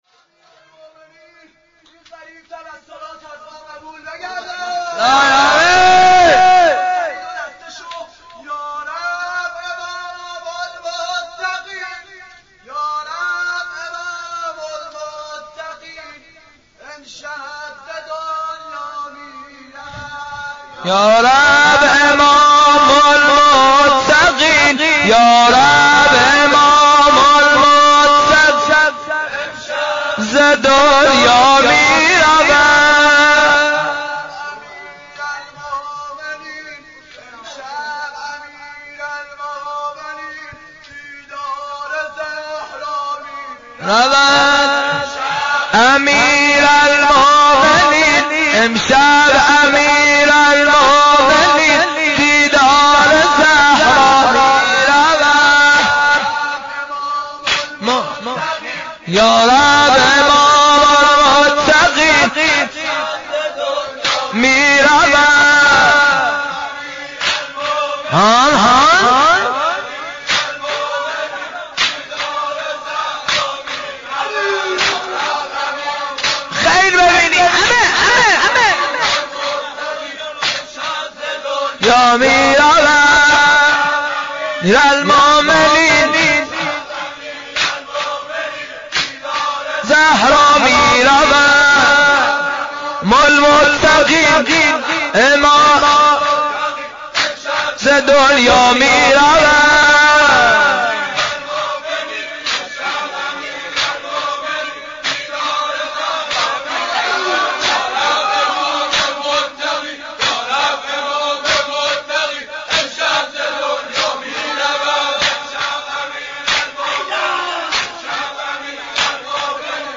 عزاداری شهادت امام علی (ع)؛ شب ۲۱ رمضان ۱۴۲۹
دریافتعنوان: شب ۲۱ ماه مبارک رمضان؛ ۱۸ دی‌ماه ۱۳۷۷حجم: 16.2 مگابایتتوضیحات: شهادت امام علی (ع)؛ مناجات با حضرت حق